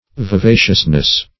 Vi*va"cious*ness, n.